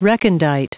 Prononciation du mot recondite en anglais (fichier audio)
Prononciation du mot : recondite